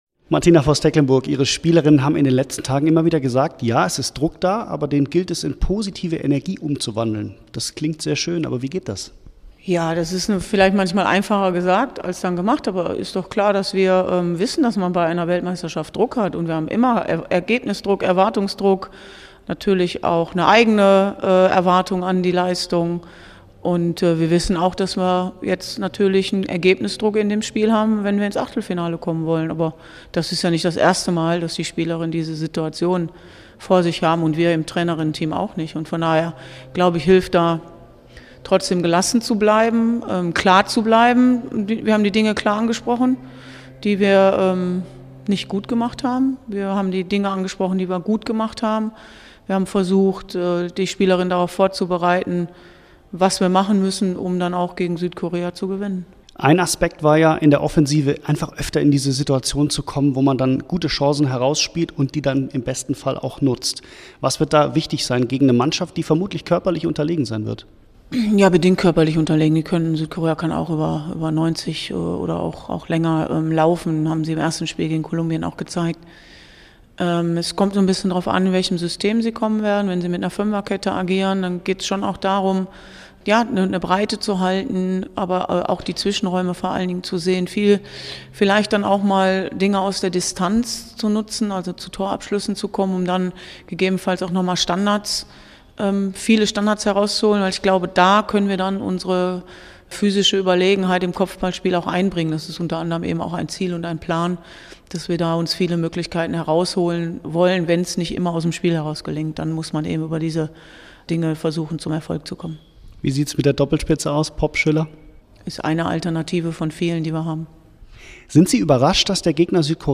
ARD-Reporter im Gespräch mit Bundestrainerin Martina Voss-Tecklenburg vor dem WM-Gruppenspiel gegen Südkorea.